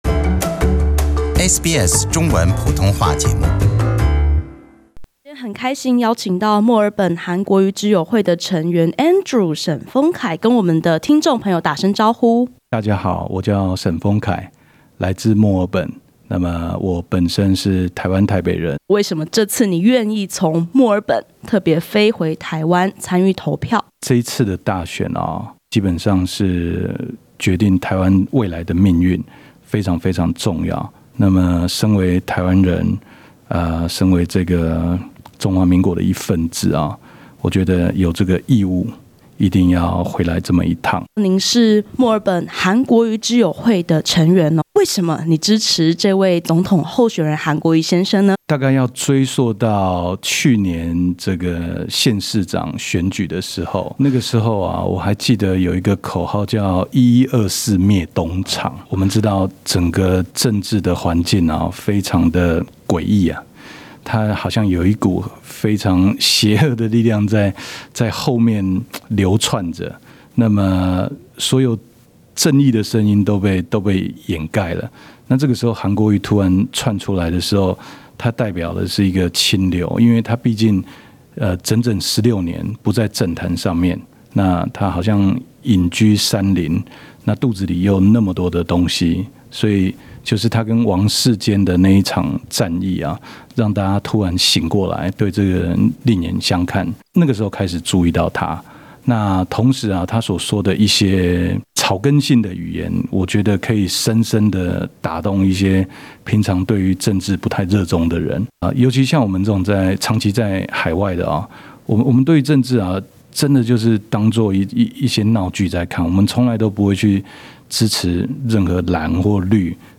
点击上方图片收听采访录音。